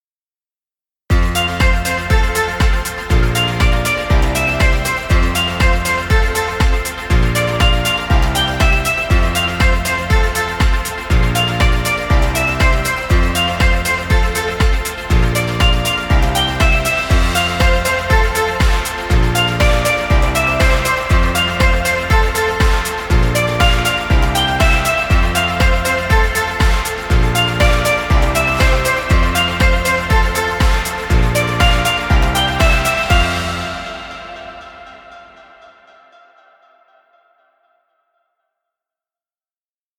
Kids music.